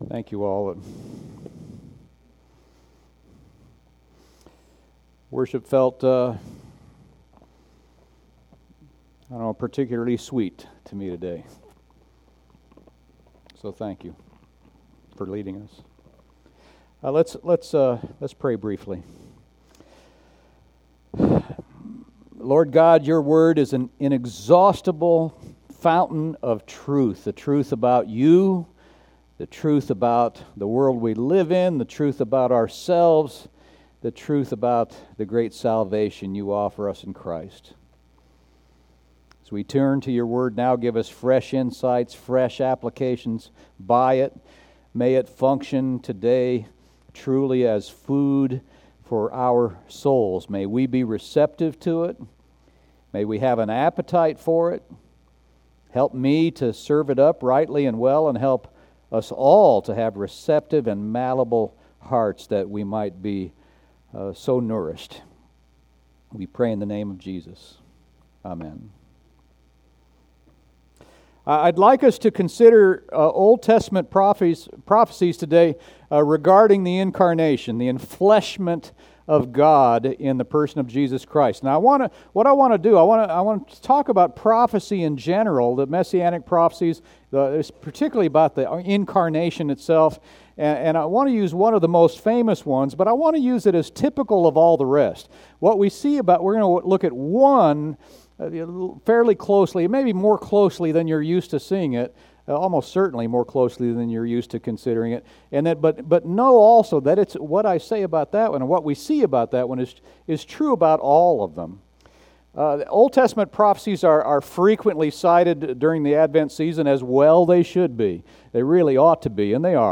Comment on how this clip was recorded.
preaches on the International Day of Prayer for the Persecuted Church